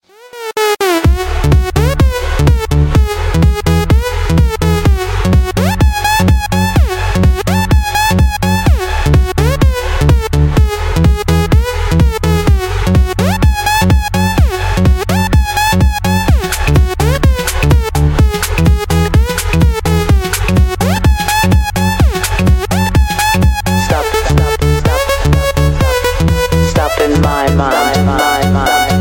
ring.ogg